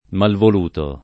malvoluto [ malvol 2 to ] agg.